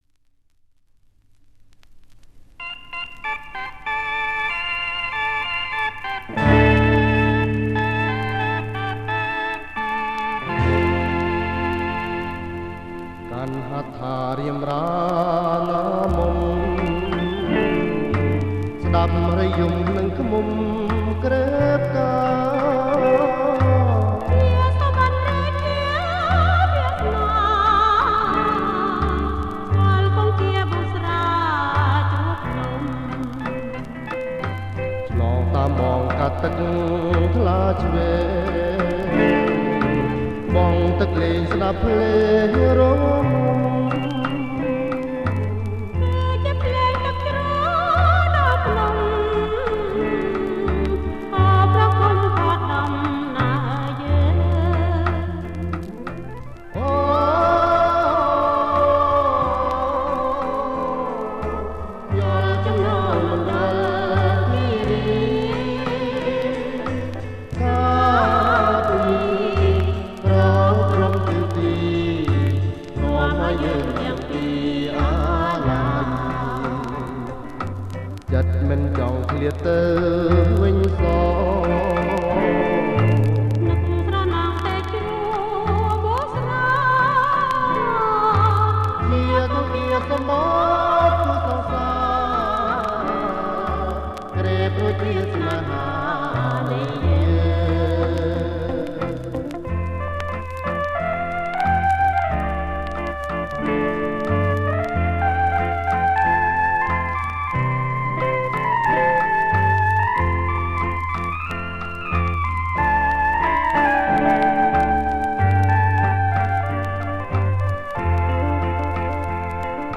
• ប្រគំជាចង្វាក់ Bolero Surf
ប្រគំជាចង្វាក់  Bolero Surf